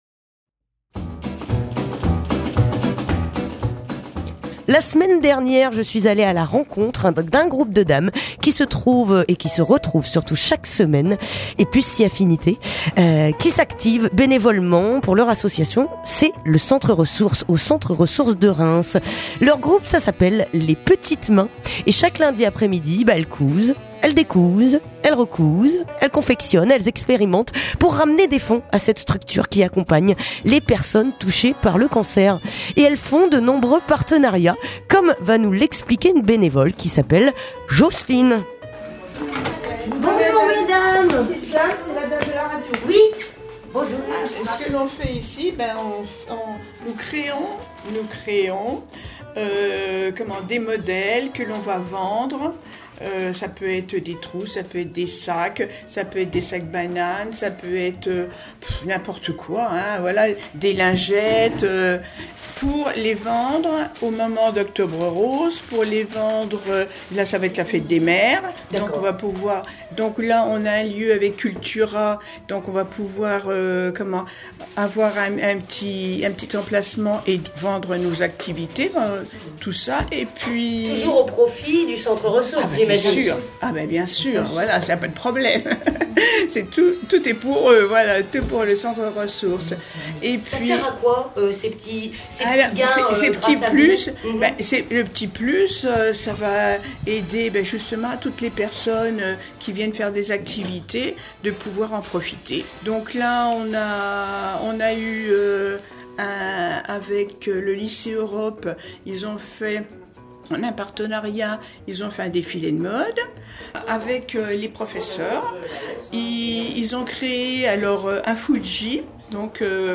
Reportage au Centre Ressource de Reims